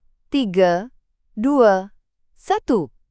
countdown321.wav